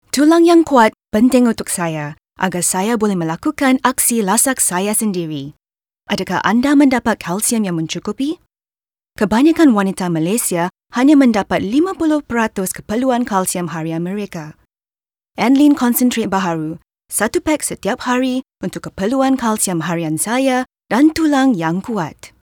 Englisch (Singapur)
Konversation
Freundlich
Natürlich